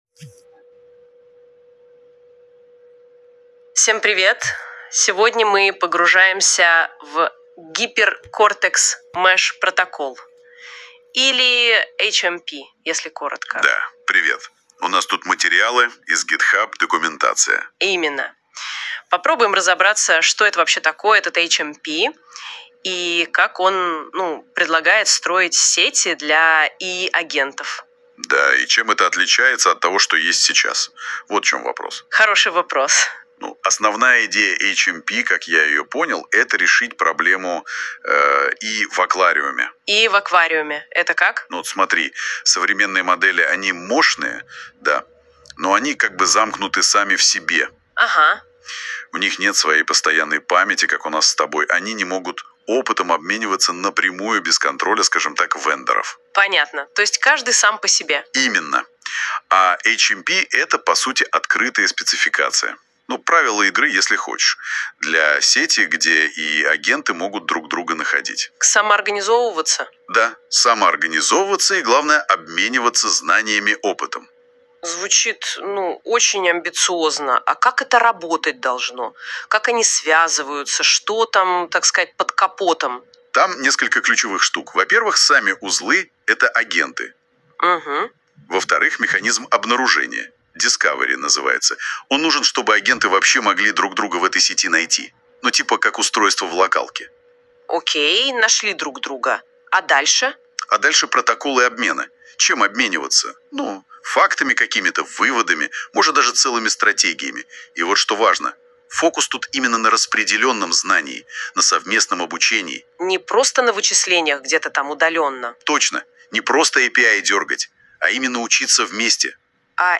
ИИ-диалог Google NotebookLM об HMP (эксперимент)